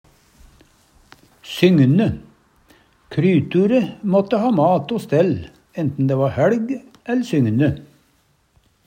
sygne - Numedalsmål (en-US)